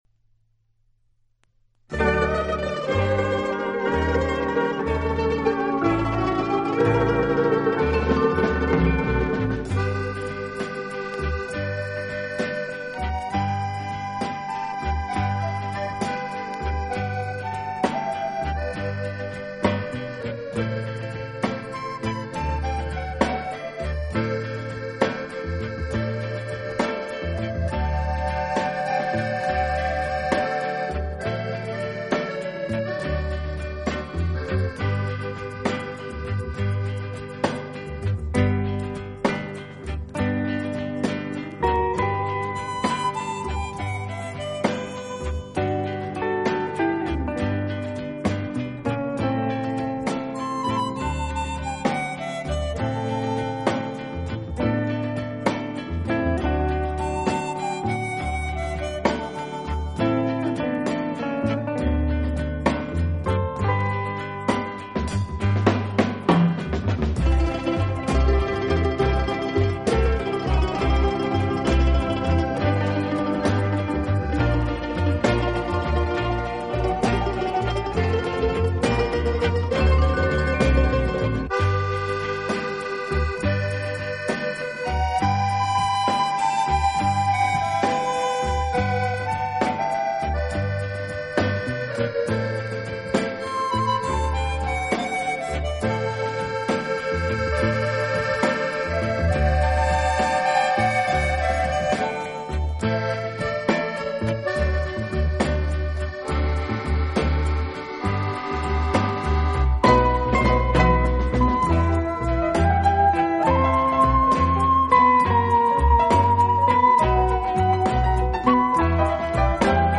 【手风琴】